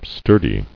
[stur·dy]